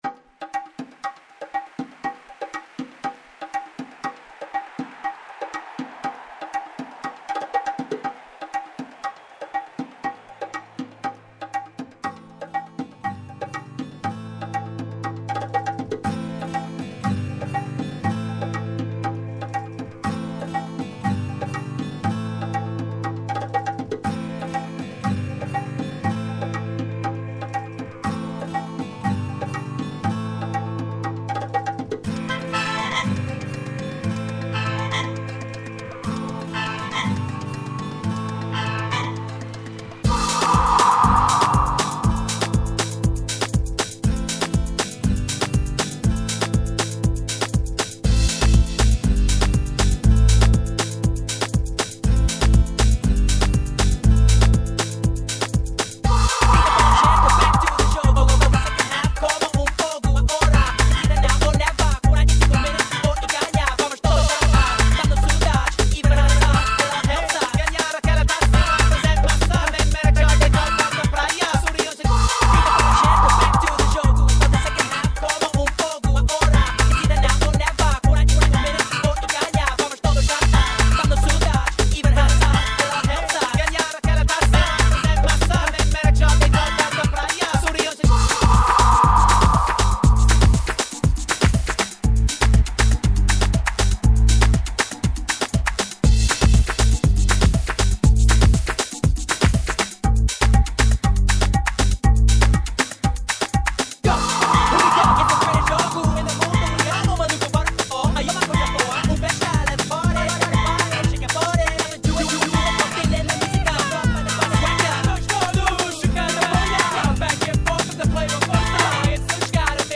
dance/electronic
Just a dance mash of sounds and drums.